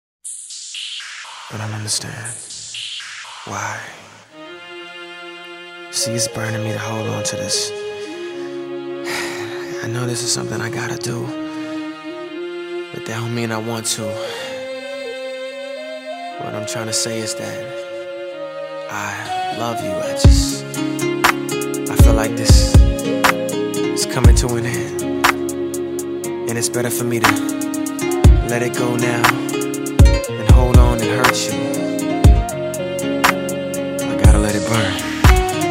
• Category Pop